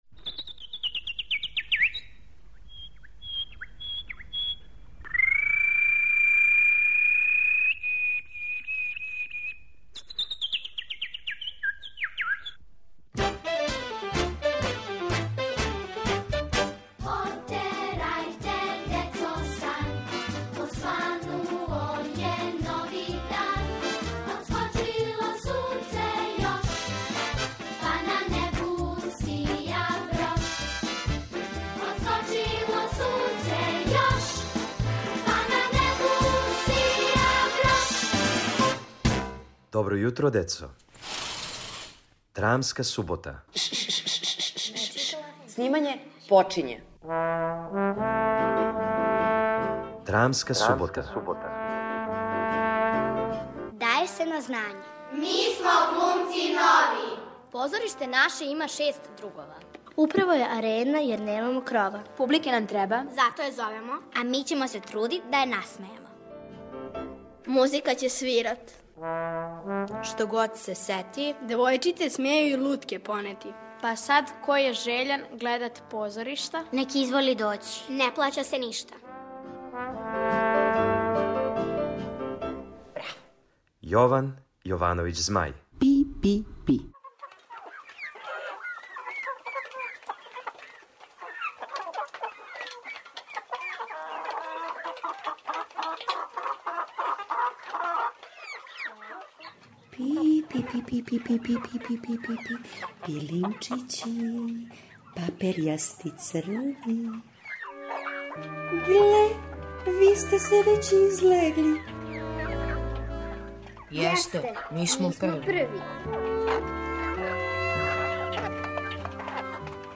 У "Драмској суботи" слушате мале драмске форме по текстовима Јована Јовановића Змаја. Данашња је настала од песме "Пи пи пи".